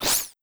Slash2.wav